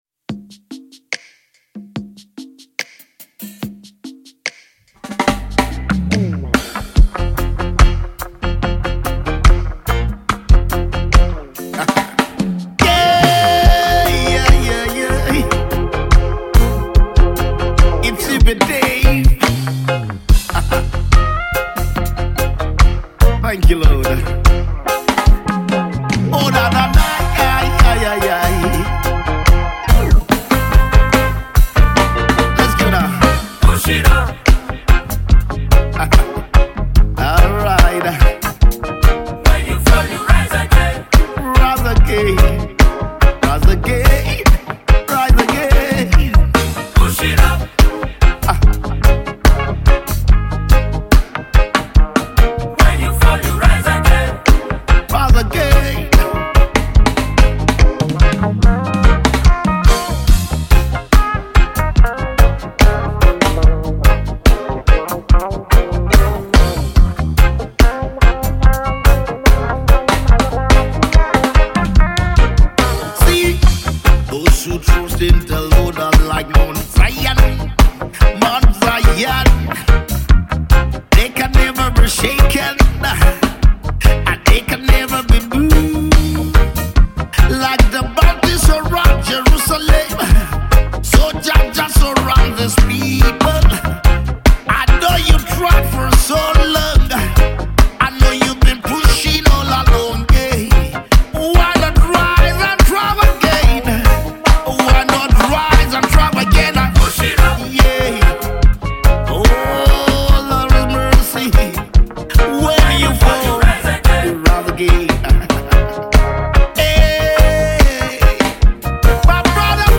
Gospel Reggae